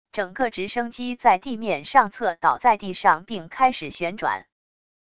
整个直升机在地面上侧倒在地上并开始旋转wav音频